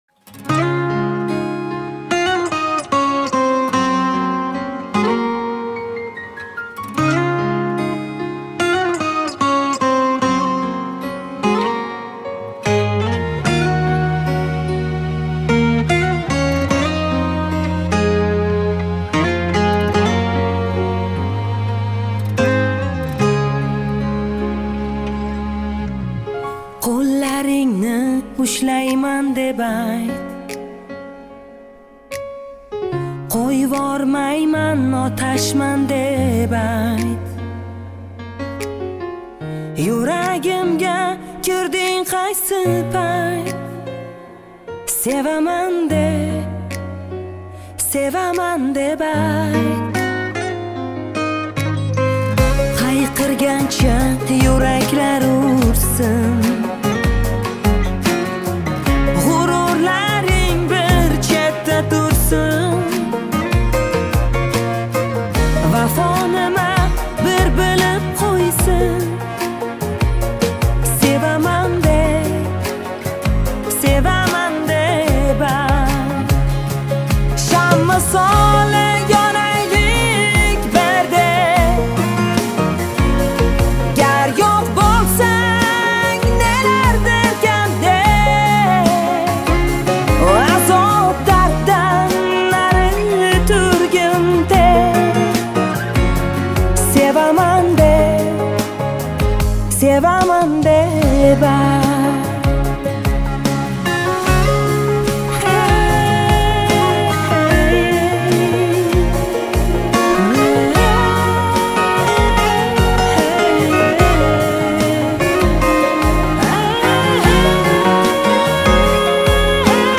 • Узбекские песни